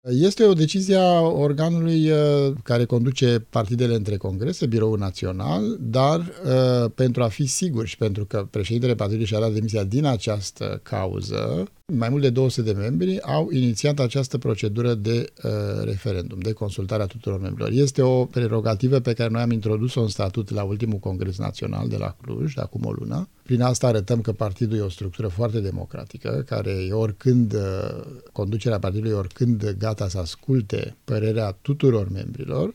Până atunci însă, în luna august, Uniunea pentru Salvarea României va face un referendum intern pe tema poziţionării faţă de revizuirea Constituţiei privind familia, a anunțat la Interviurile Europa FM, vicepreședintele Uniunii, Vlad Alexandrescu.